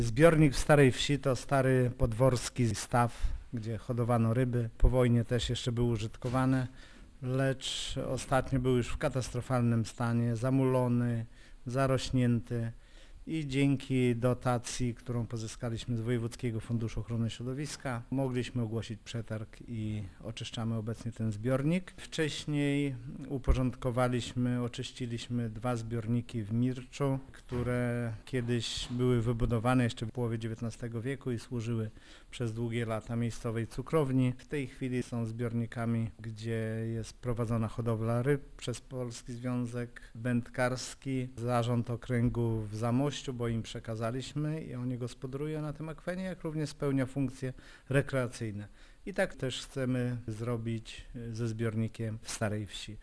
Wójt Lech Szopiński przypomina, że to jedna z kilku tego typu inwestycji w gminie: